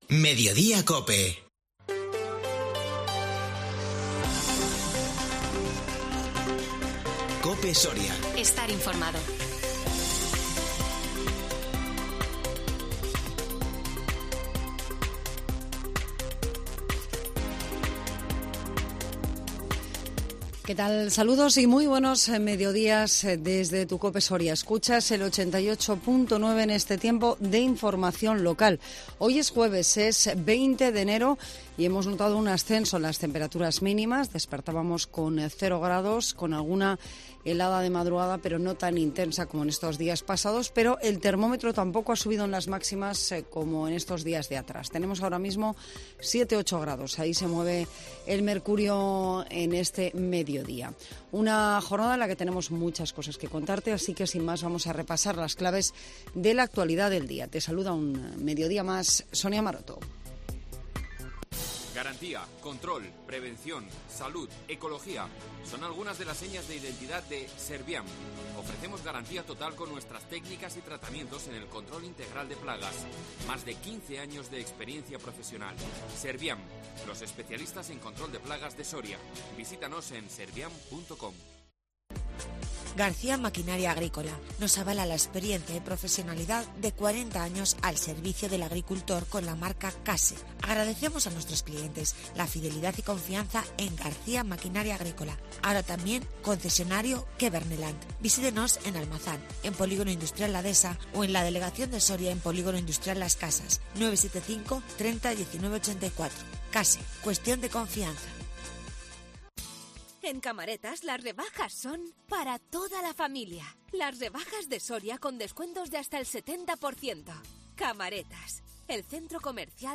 INFORMATIVO MEDIODÍA 20 ENERO 2021